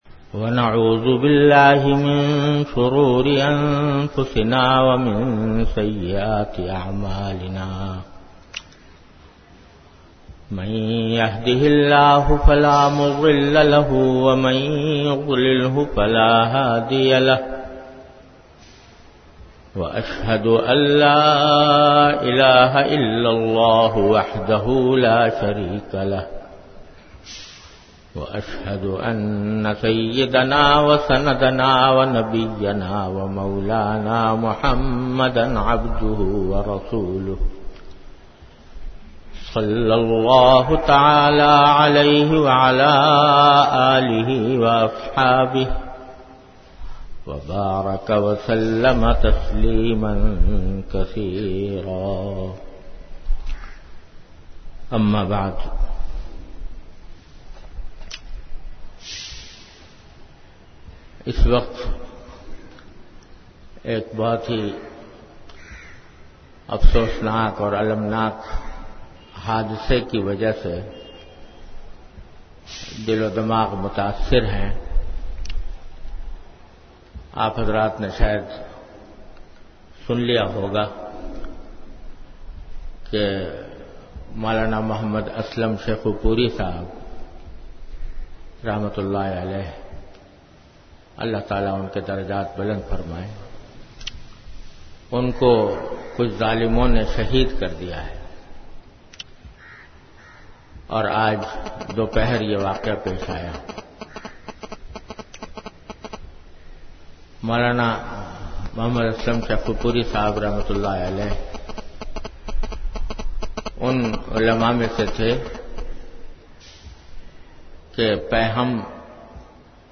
An Islamic audio bayan by Hazrat Mufti Muhammad Taqi Usmani Sahab (Db) on Bayanat. Delivered at Darululoom Karachi.